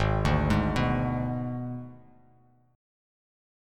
G#dim Chord
Listen to G#dim strummed